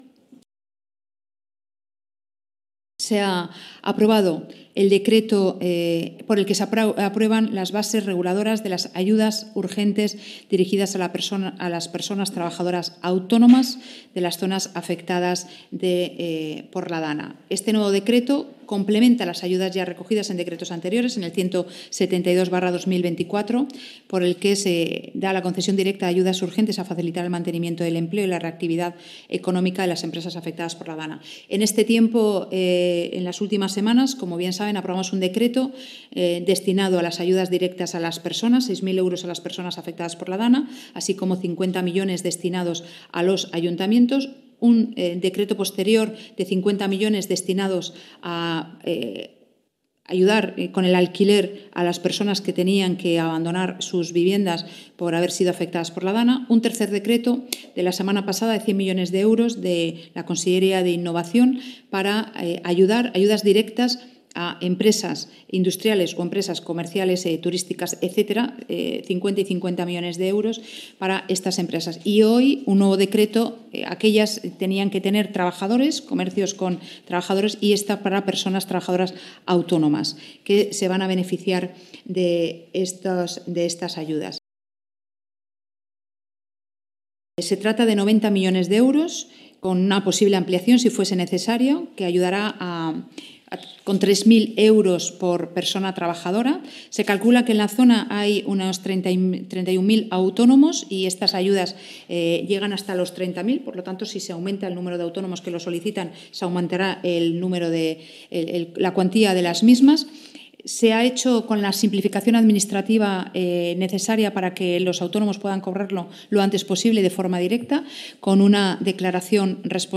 El Consell ha aprobado un decreto que contempla ayudas directas por valor de 90 millones de euros dirigidas a personas trabajadoras autónomas sin personal empleado de las zonas afectadas por la riada, tal y como ha informado la vicepresidenta primera y portavoz, Susana Camarero, en la comparecencia posterior a los plenos ordinario y extraordinario del Consell.